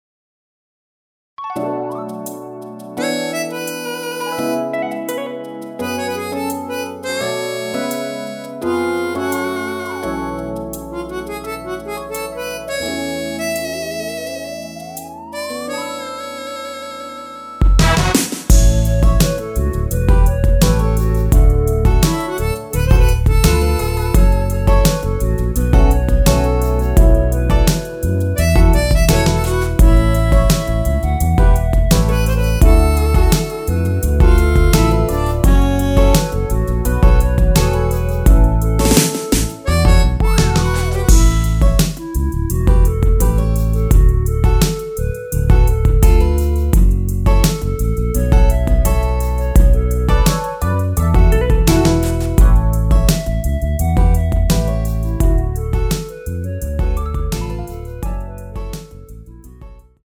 원키에서(-5)내린 멜로디 포함된 MR입니다.
앞부분30초, 뒷부분30초씩 편집해서 올려 드리고 있습니다.
중간에 음이 끈어지고 다시 나오는 이유는